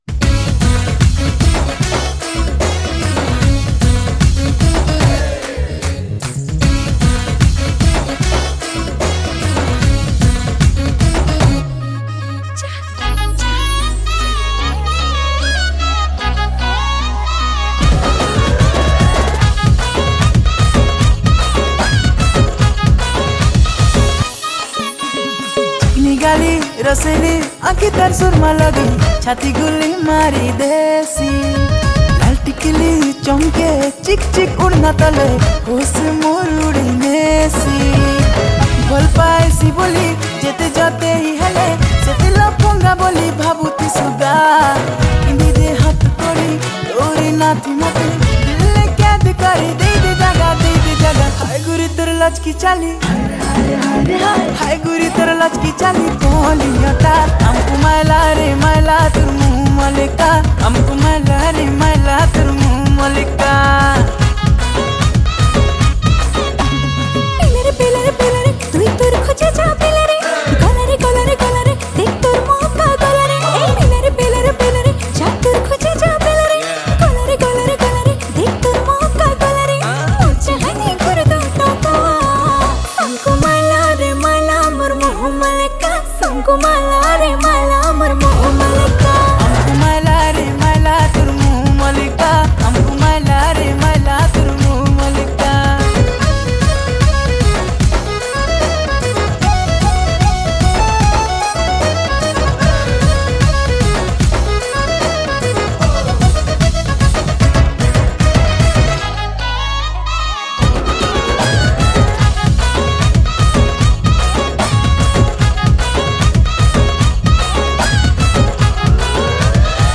Category: New Sambalpuri